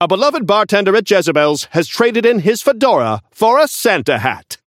Newscaster voice line - A beloved bartender at Jezebel's has traded in his fedora for a Santa hat!
Newscaster_seasonal_inferno_unlock_01.mp3